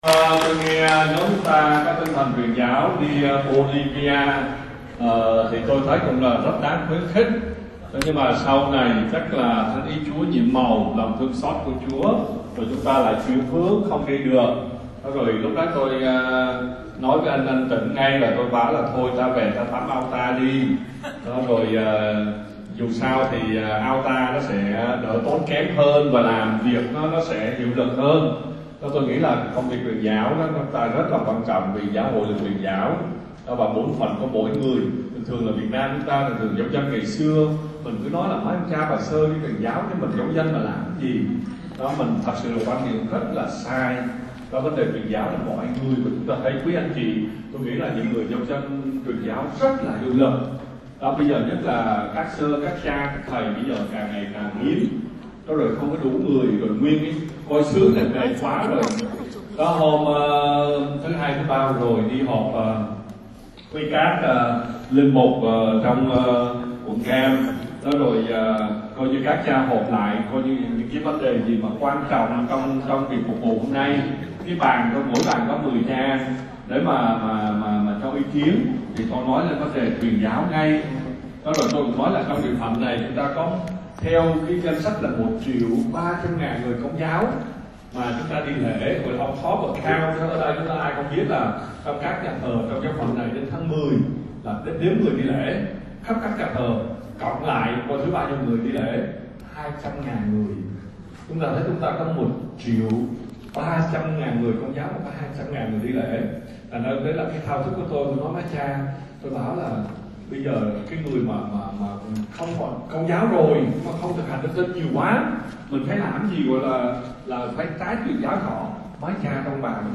Lời huấn từ Giáng Sinh